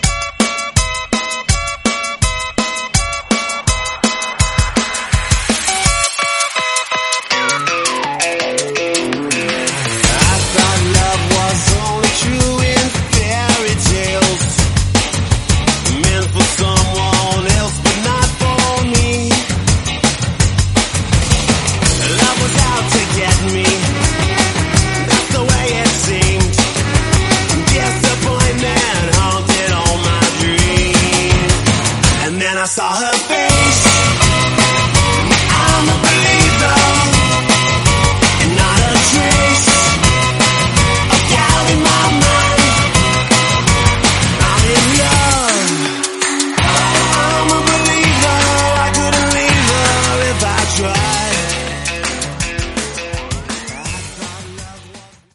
Genre: DANCE
Clean BPM: 130 Time